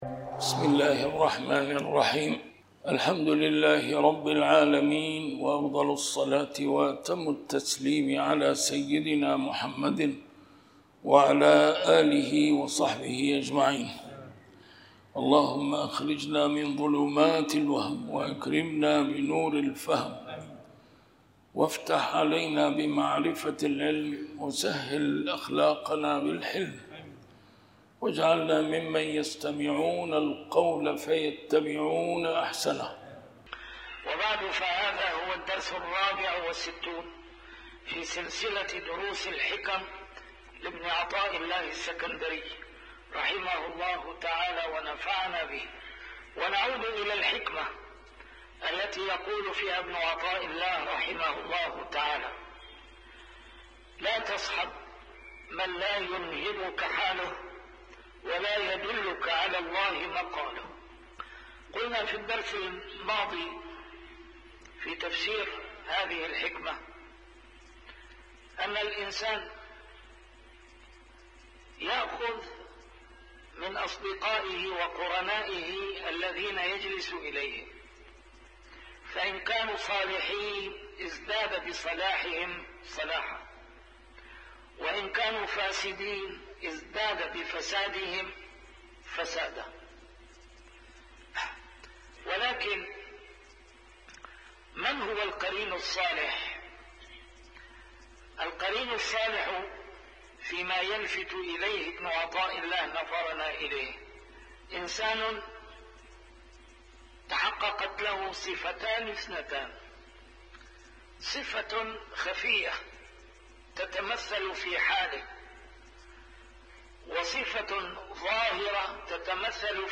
A MARTYR SCHOLAR: IMAM MUHAMMAD SAEED RAMADAN AL-BOUTI - الدروس العلمية - شرح الحكم العطائية - الدرس رقم 64 شرح الحكمة 43